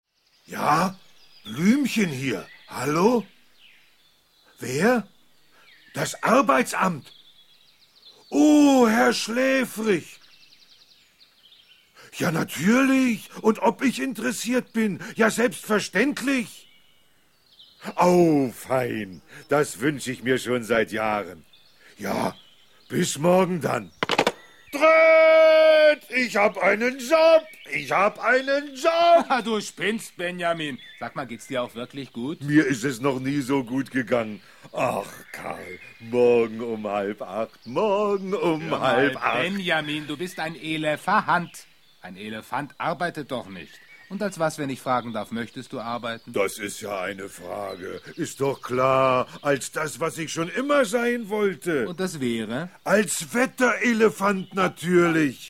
Ravensburger Benjamin Blümchen - ...als Wetterelefant ✔ tiptoi® Hörbuch ab 3 Jahren ✔ Jetzt online herunterladen!